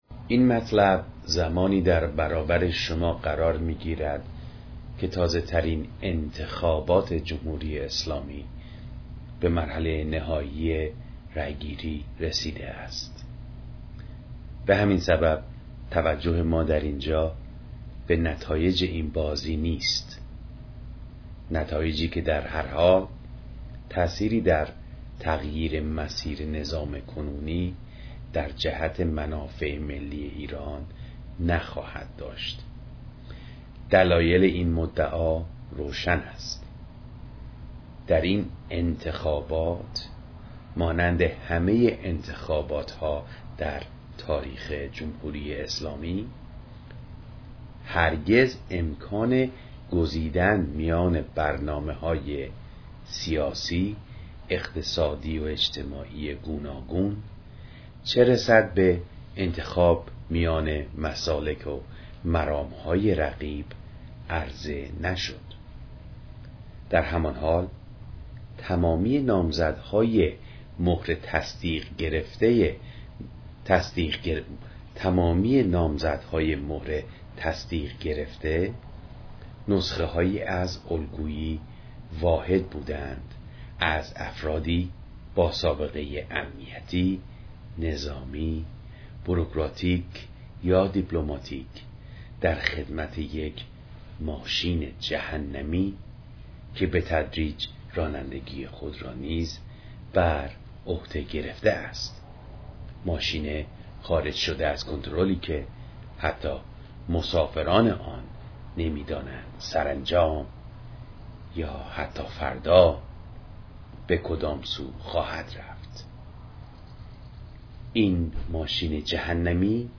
روخانی مقاله استاد تقدیمتان می‌شود.